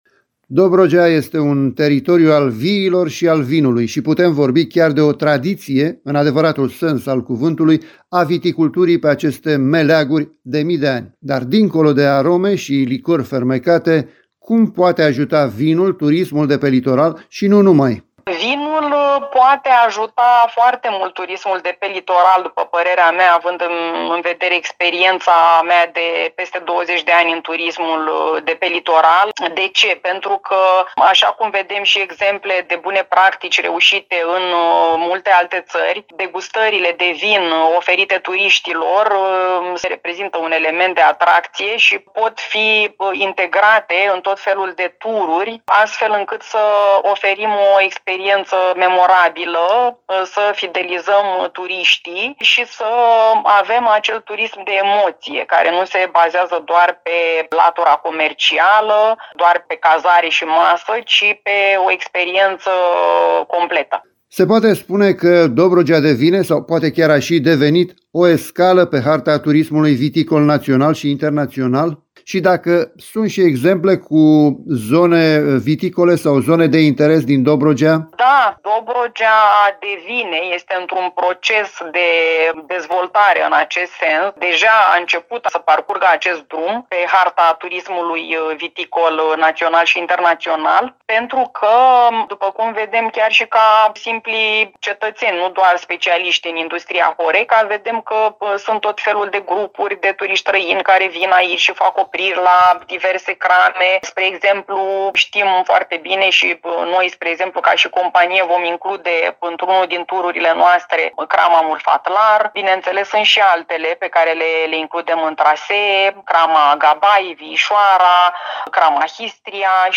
a dialogat despre acest eveniment și despre vinul dobrogean